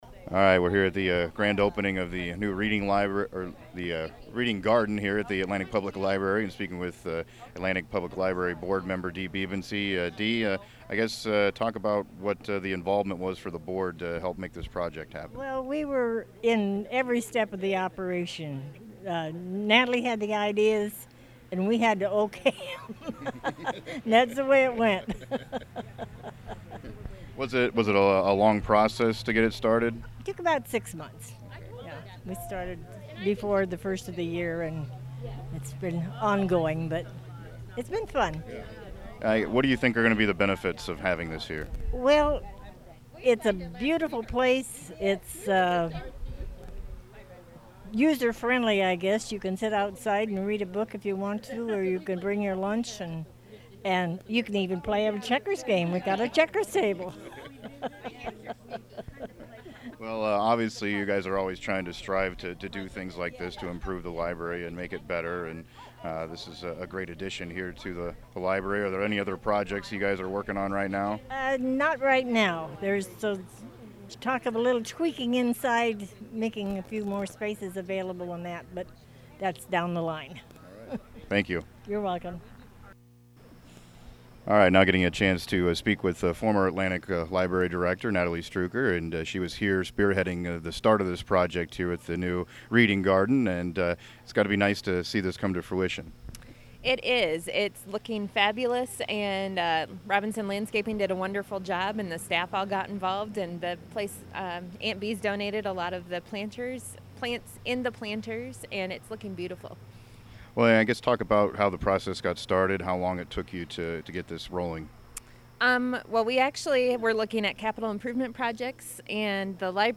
Reading Garden Grand Opening held at Atlantic Public Library
LIBRARYREADINGGARDEN.mp3